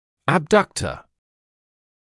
[əb’dʌktə][эб’дактэ]абдуктор, отводящая мышца